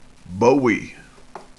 THE MOST COMMONLY USED LOCAL PRONUNCIATIONS
Bowie (BOO-wee) is a Texas pioneer and namesake for South Austin's James Bowie High School.